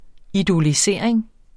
Udtale [ idoliˈseɐ̯ˀeŋ ]